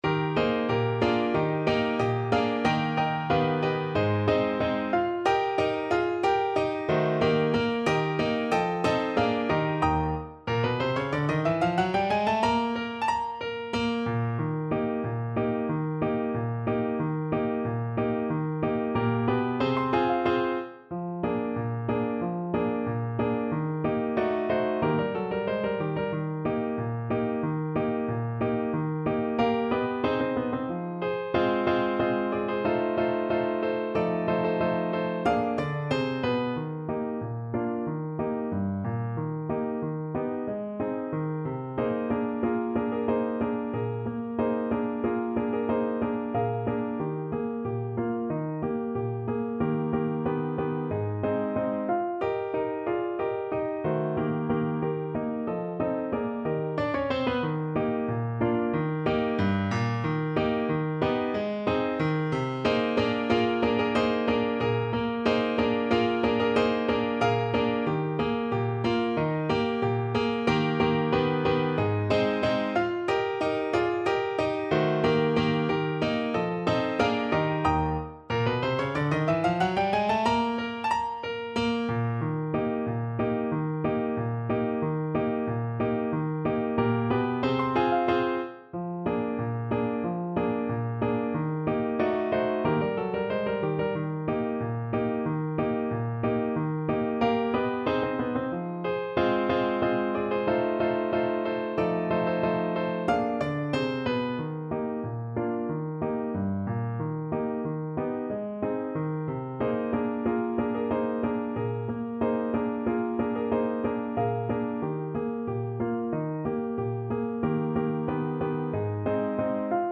Allegretto =92